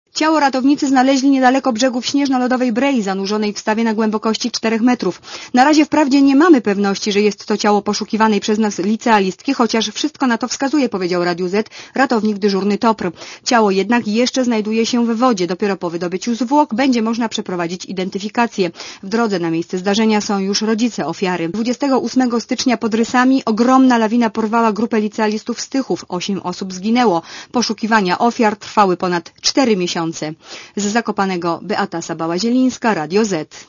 Komentarz audio (132Kb)